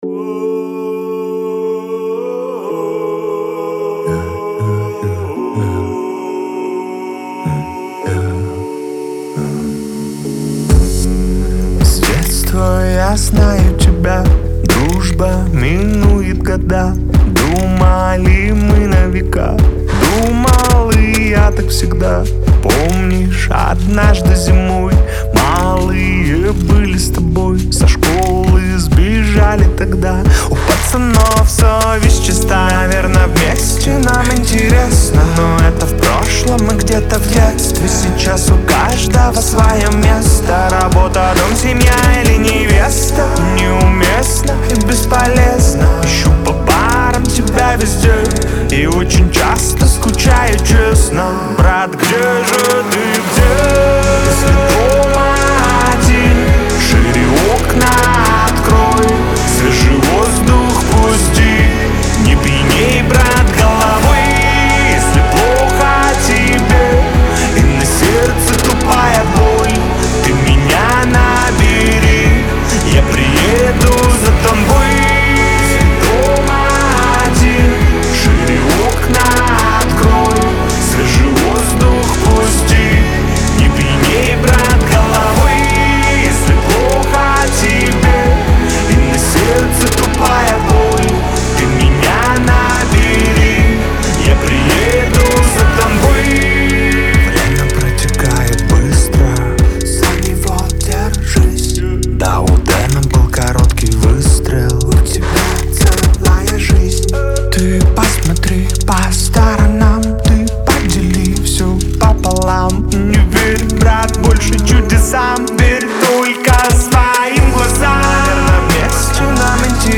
это яркий пример сочетания регги и хип-хопа